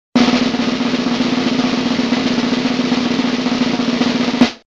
Hiệu ứng âm thanh DRUM ROLL - Tải Mp3
Click vào đây để tải âm thanh DRUM ROLL về máy, hiệu ứng âm thanh dùng để để edit video, dựng video